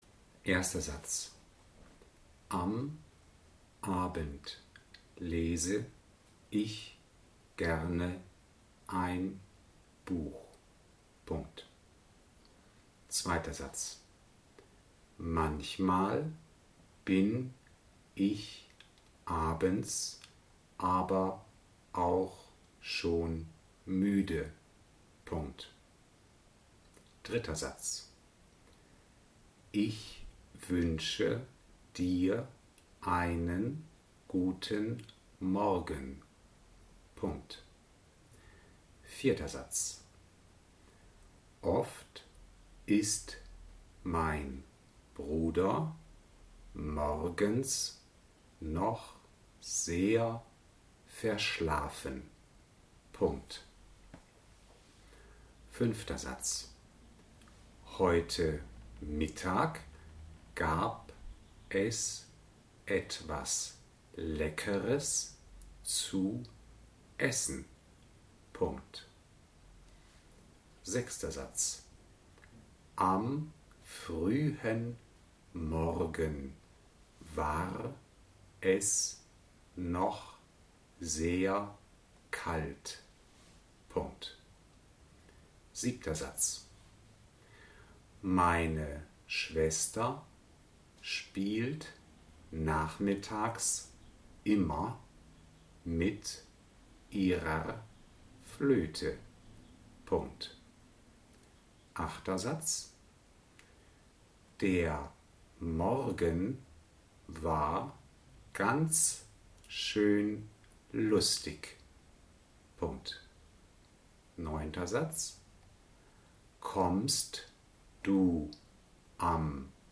Diktate als MP3
Darin sind die Übungssätze Wort für Wort mit kleinen Pausen diktiert.